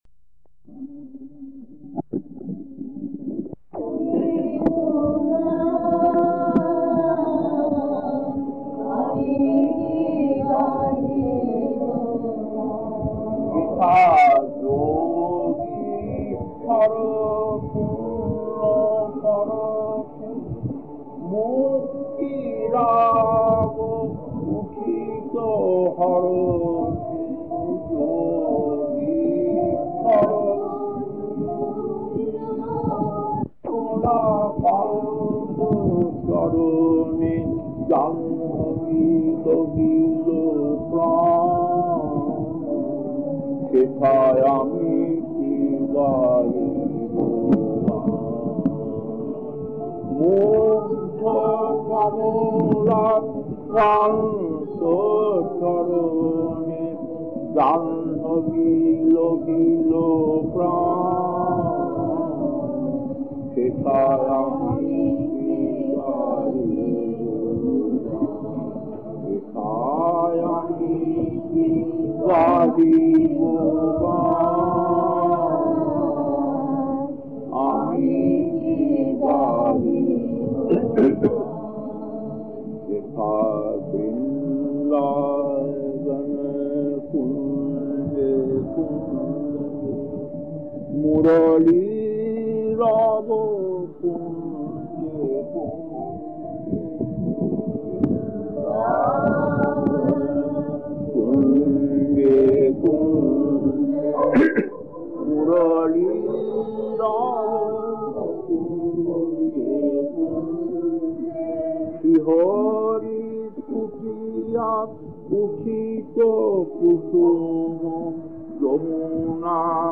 Kirtan B1-1 Chennai 1994, 46 minutes 1.